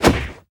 Minecraft Version Minecraft Version 1.21.5 Latest Release | Latest Snapshot 1.21.5 / assets / minecraft / sounds / entity / player / attack / knockback2.ogg Compare With Compare With Latest Release | Latest Snapshot
knockback2.ogg